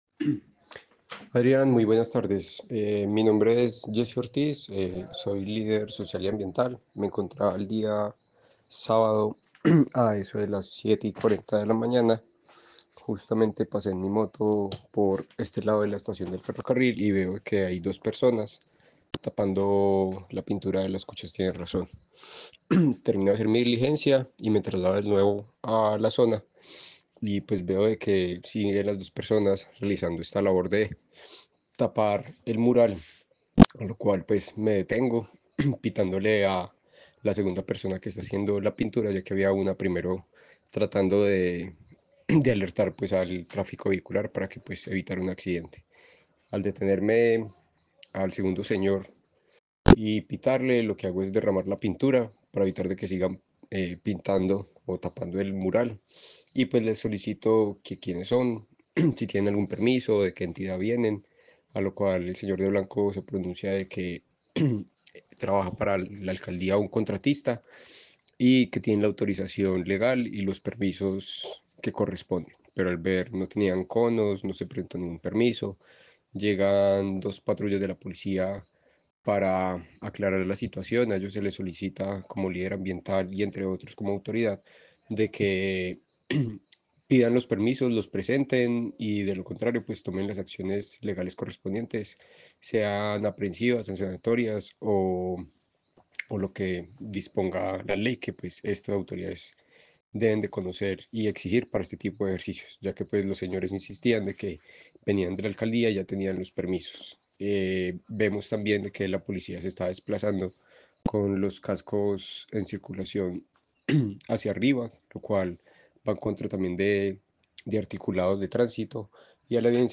Líder social